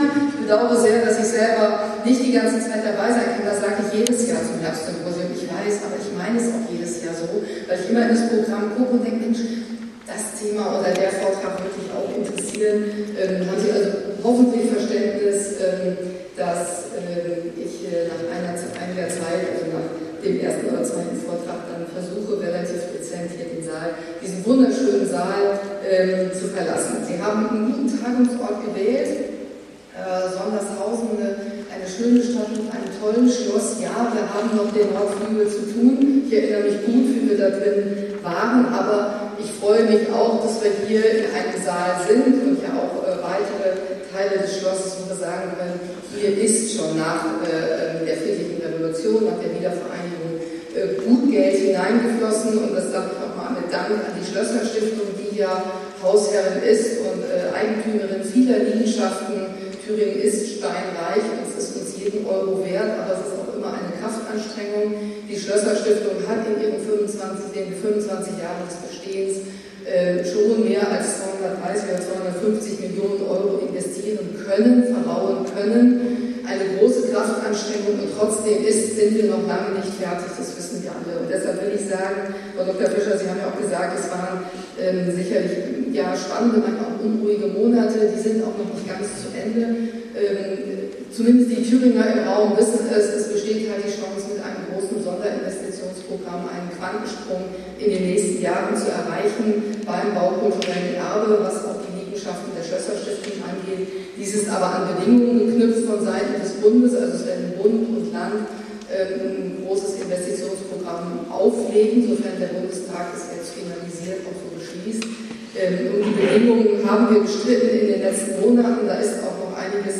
Statt im großen Riesensaal von Schloss Sondershausen war das Symposium in den kleineren Blausen Salon verlegt worden.
Rede Dr. Winter 2